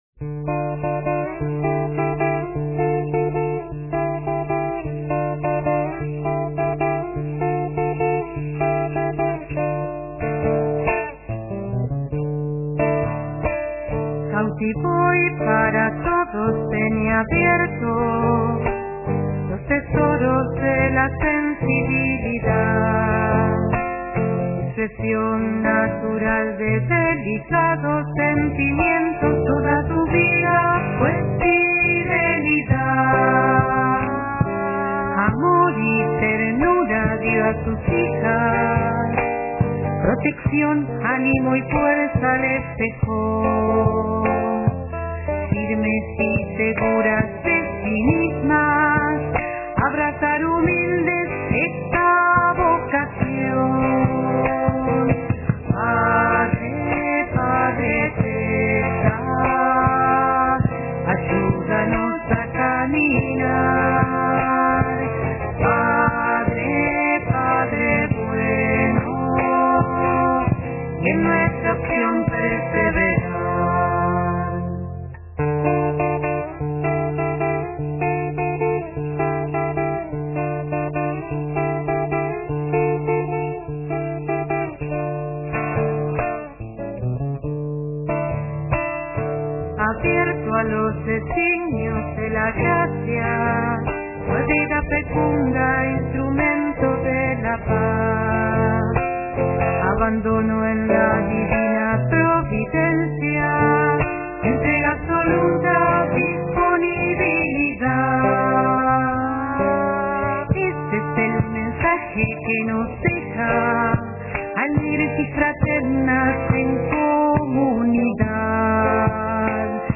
Mensajes (Zamba) Cautivó y para todos tenía abiertos, los tesoros de la sensibilidada, expresión natural de delicados sentimientos, toda su vida fue fidelidad.